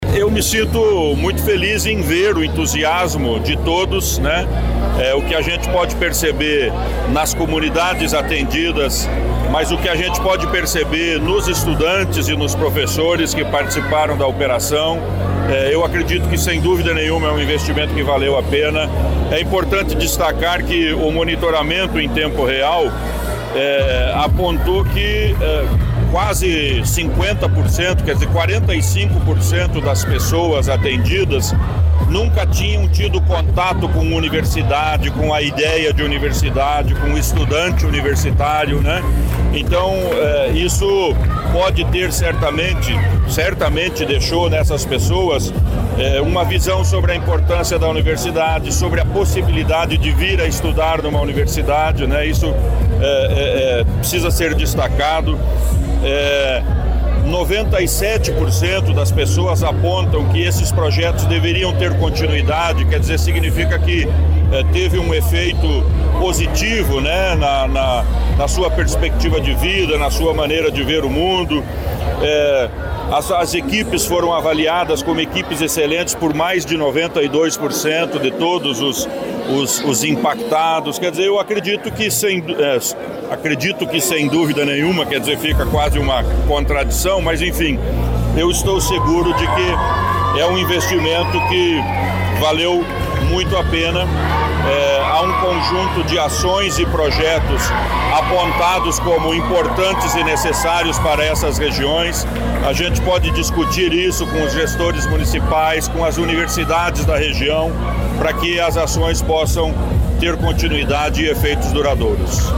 Sonora do secretário da Ciência, Tecnologia e Ensino Superior, Aldo Bona, sobre o encerramento da Operação Rondon Paraná